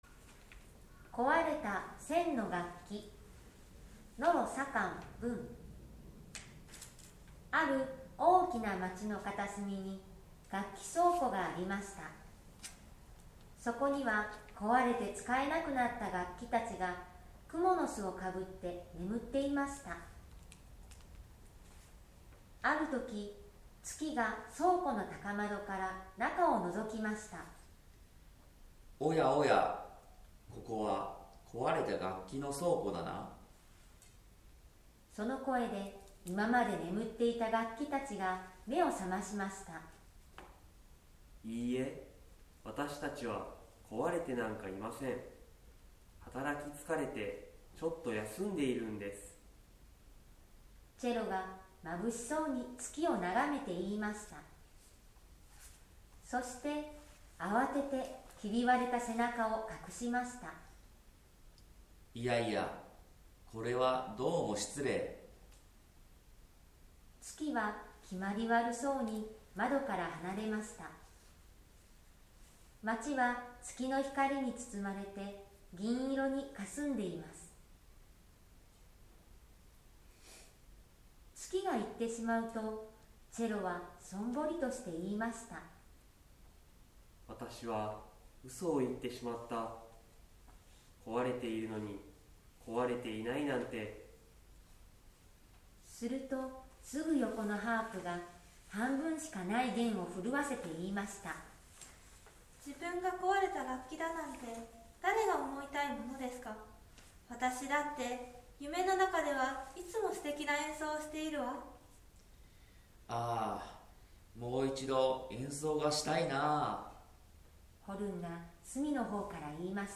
そこで、参考になればと、4年生の担任が集まって音読をしました。